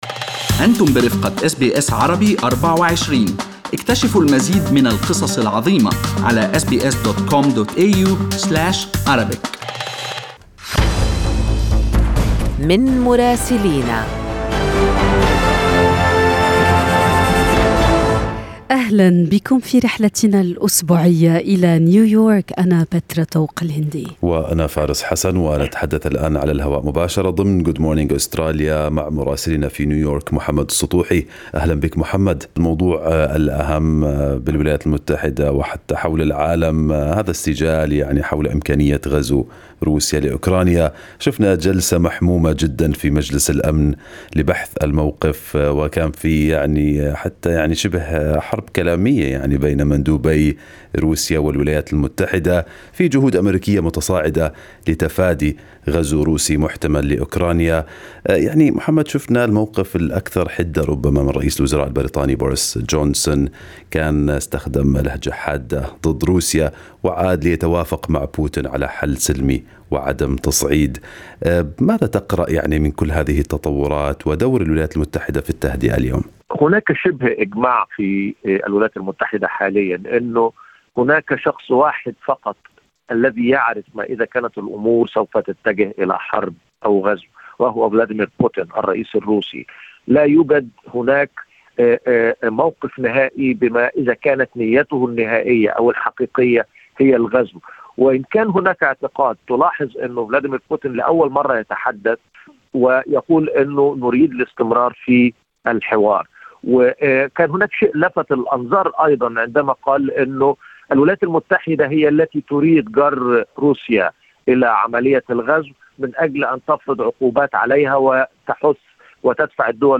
من مراسلينا: أخبار الولايات المتحدة الأمريكية في أسبوع 3/2/2022